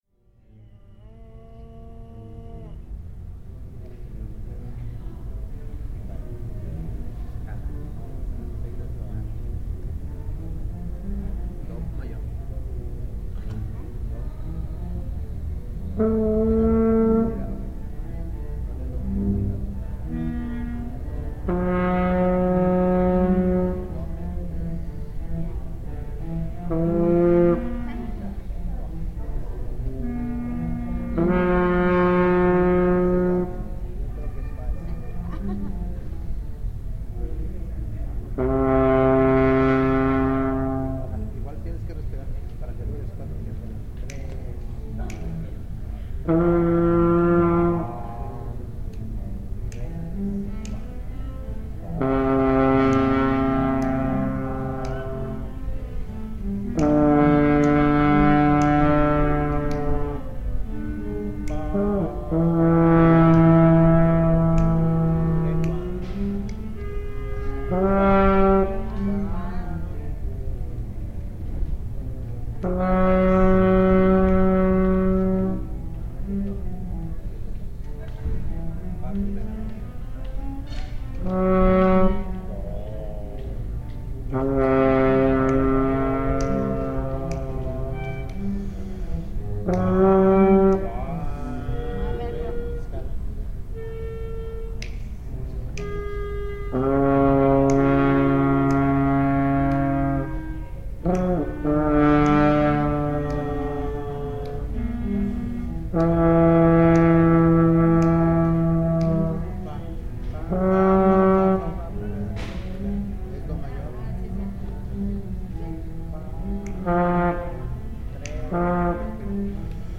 Práctica de Trombón
Una práctica sonoro con un trombón perteneciente a la Orquesta Sinfónica Juvenil de Chiapas, grabado en el Ágora del Centro Cultural de Chiapas Jaime Sabines en la ciudad de Tuxtla Gutierrez.